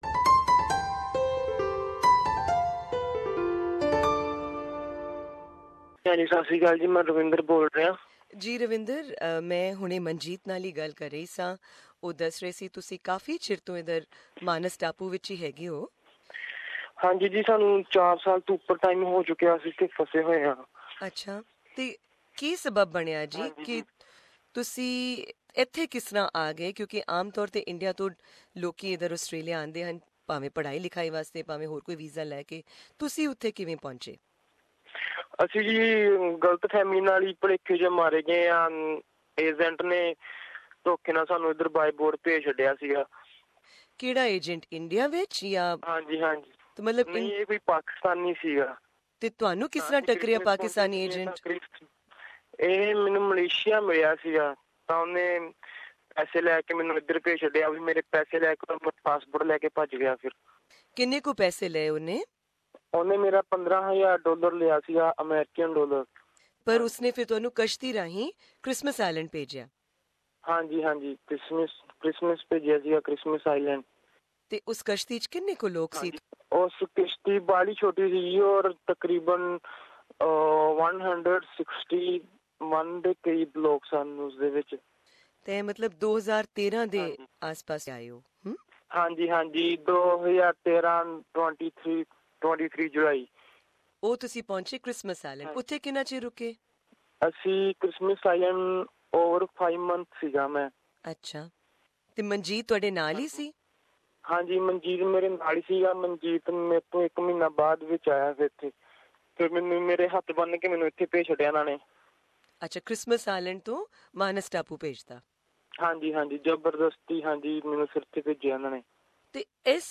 'We are in danger' says a Punjabi detainee inside Manus Island detention centre